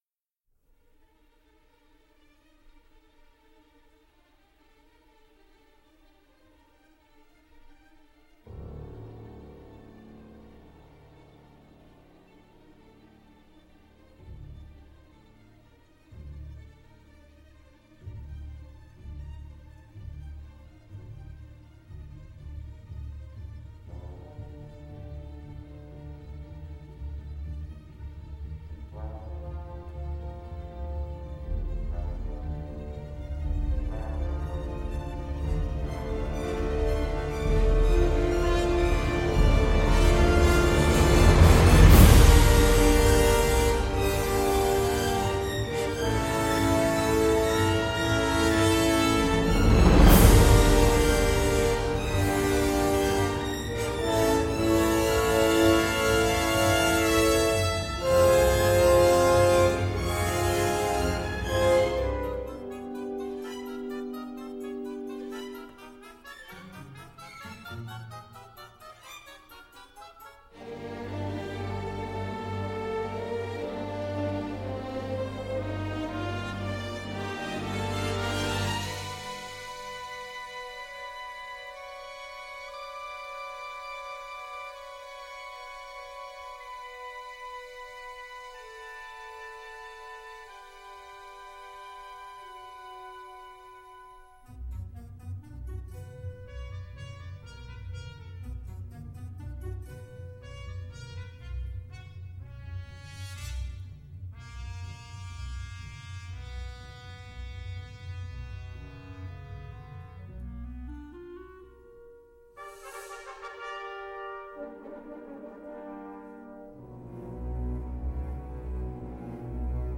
La touche originale étant ici l’ambiance un peu foraine.